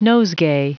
Prononciation du mot : nosegay
nosegay.wav